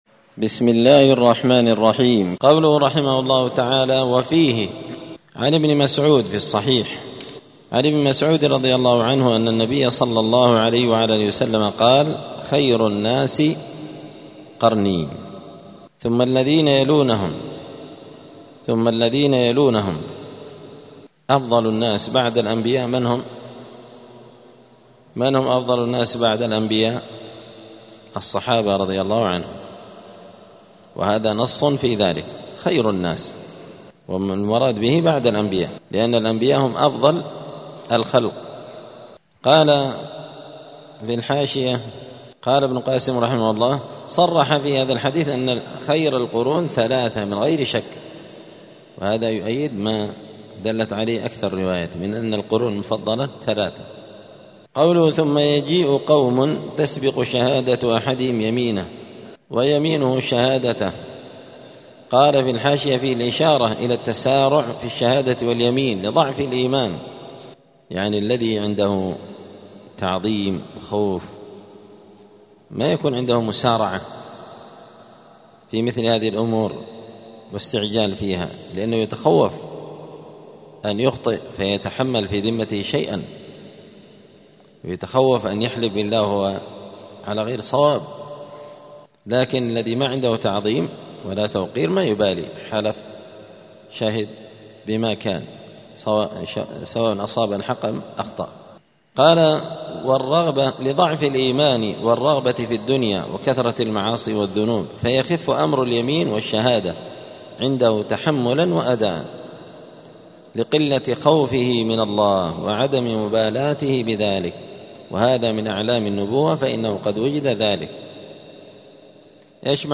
دار الحديث السلفية بمسجد الفرقان قشن المهرة اليمن
*الدرس التاسع والأربعون بعد المائة (149) {باب ما جاء في ذمة الله وذمة نبيه}*